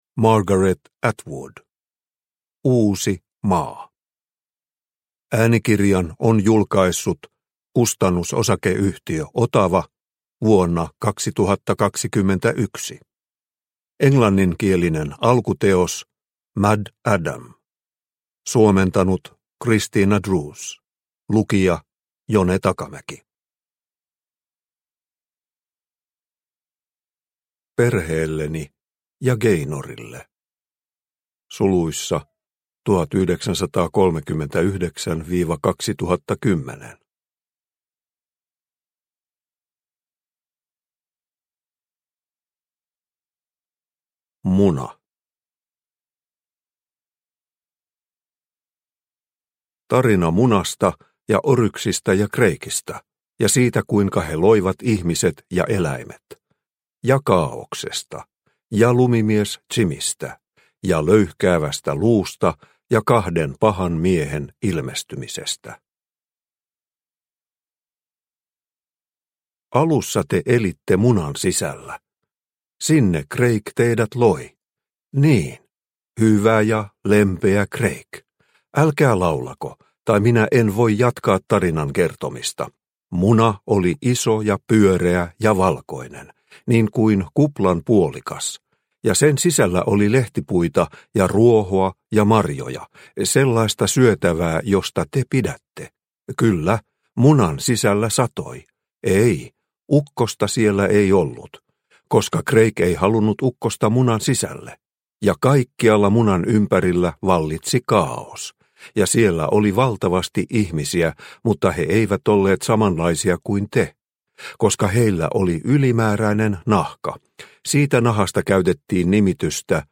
Uusi maa – Ljudbok – Laddas ner